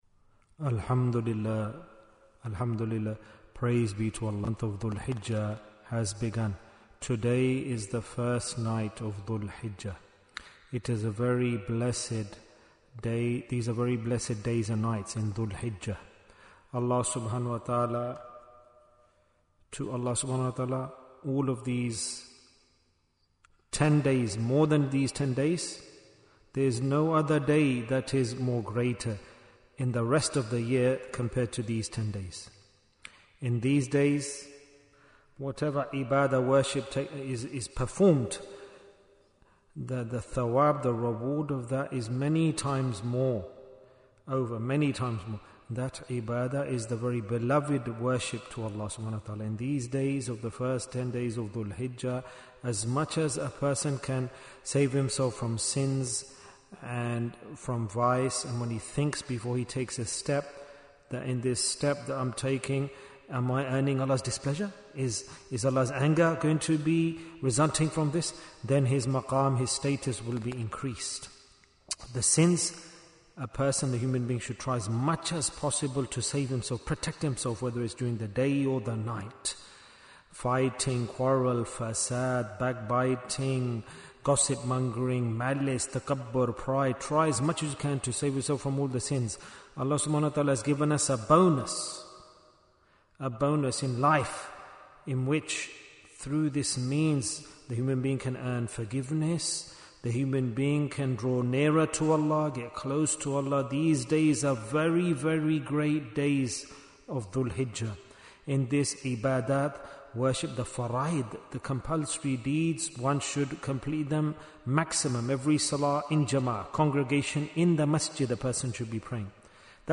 Talk before Dhikr 195 minutes27th May, 2025